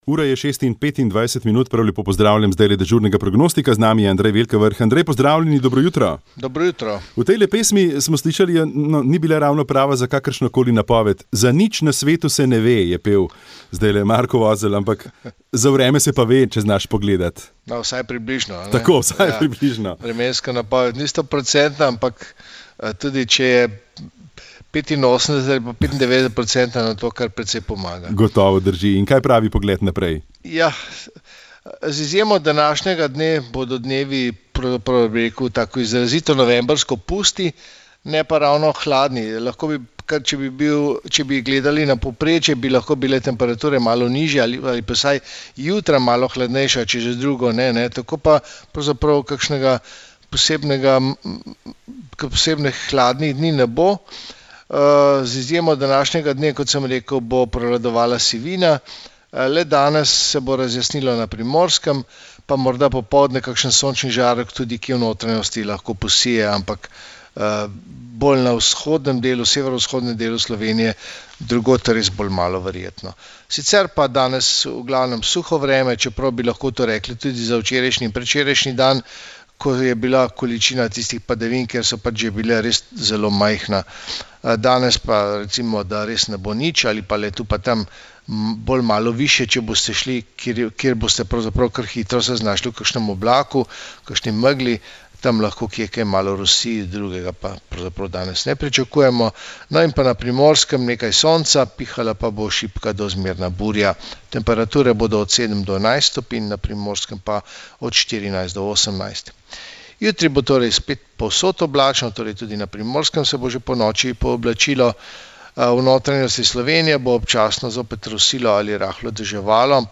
Vremenska napoved 15. november 2021